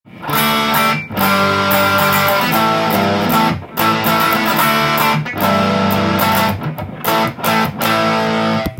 ロックで渋いエレキギター【MUSICMAN Axis】
ハムバッカーのピックアップなのでクリーントーンが非常に太く
中音域が強調されています。
アームが効きやすく激しい音程差が楽しめます。